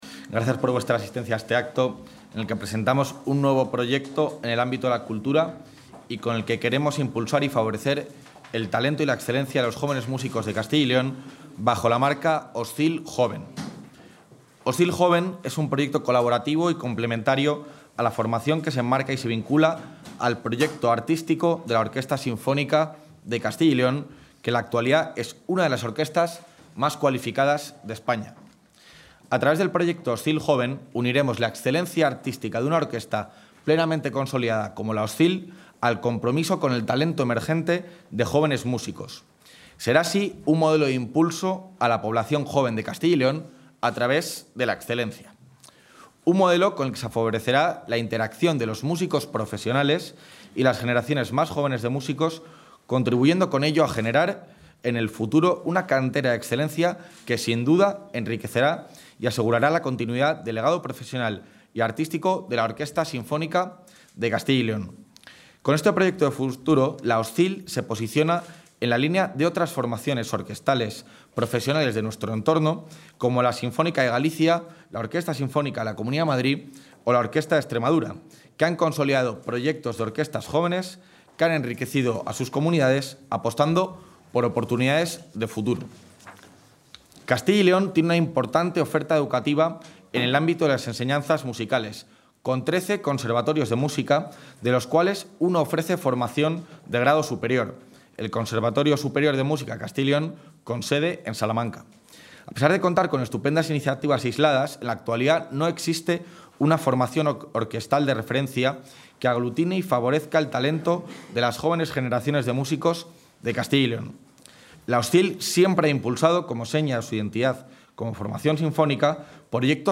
Intervención del vicepresidente.